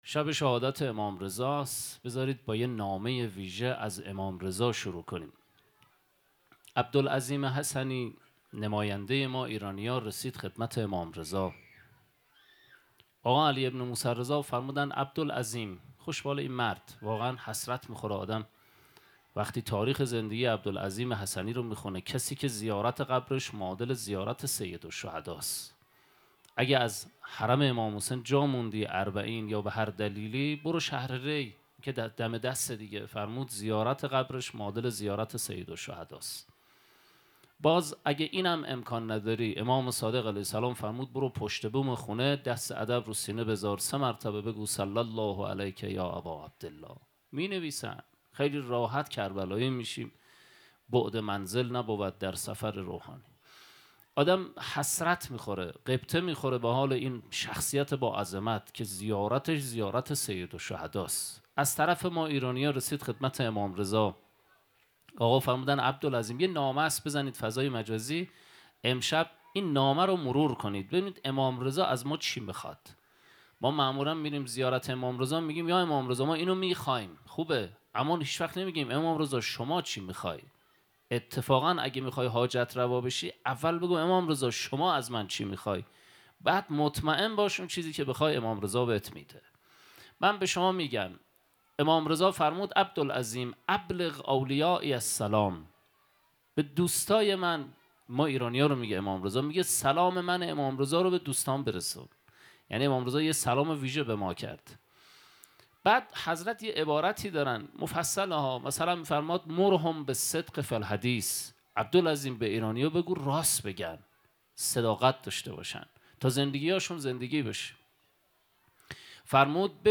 در شب شهادت حضرت علی ابن موسی الرضا ویژه مراسمی در جمع خانواده شهداء و درکنار قبور مطهر فرزندانشان برگزار شد.